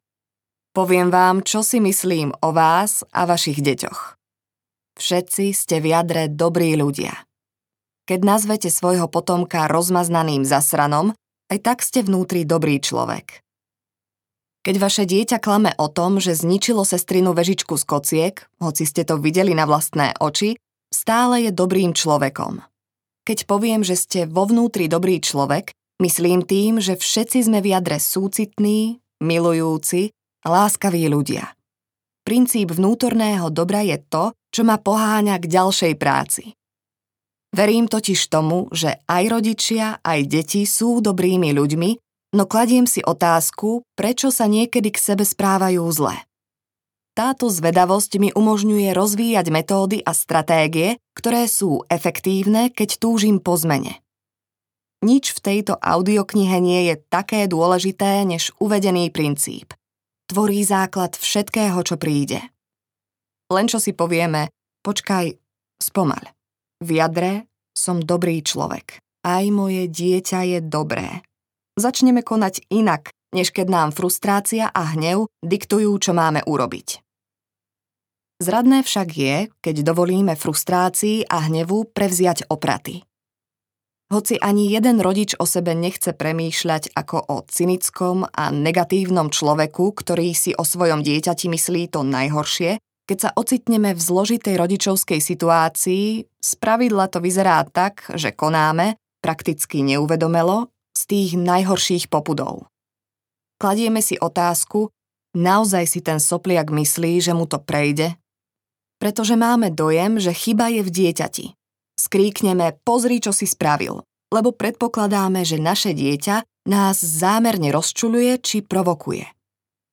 Objavte v sebe dobrého rodiča audiokniha
Ukázka z knihy
objavte-v-sebe-dobreho-rodica-audiokniha